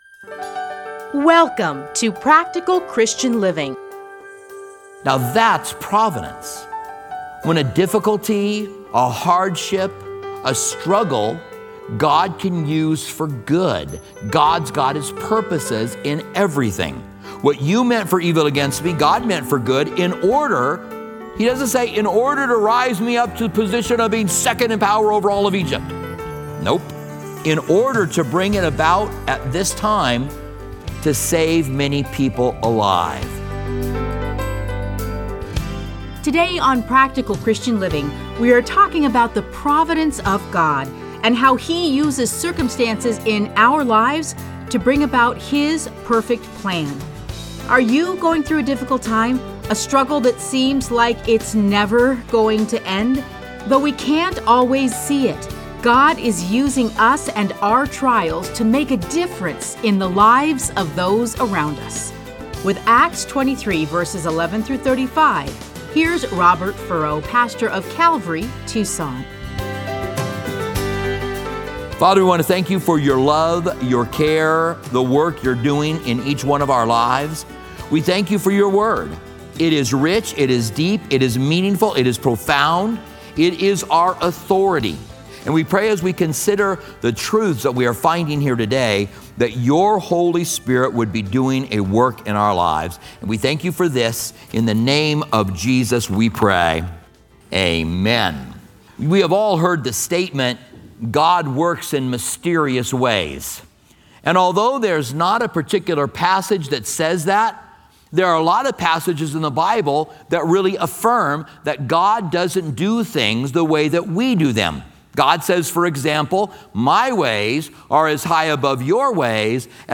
Listen to a teaching from Acts 23:11-35.